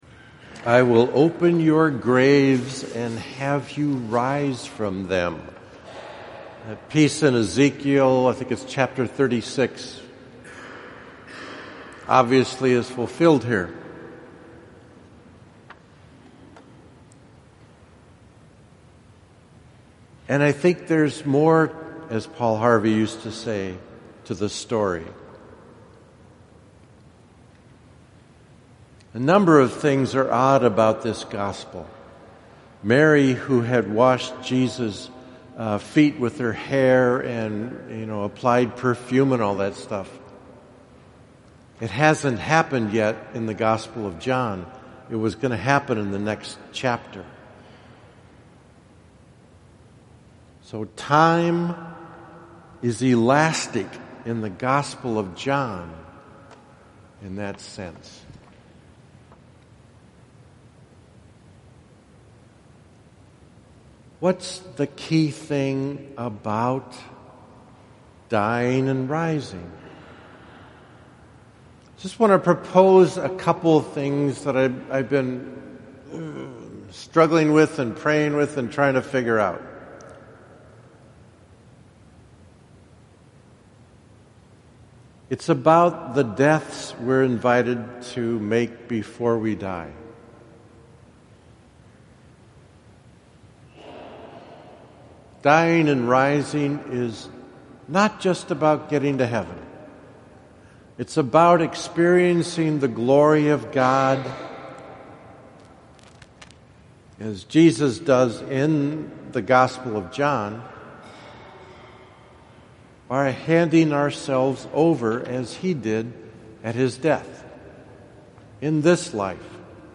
5th SUN LENT – Homily 1 (Audio Version)